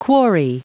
Prononciation du mot quarry en anglais (fichier audio)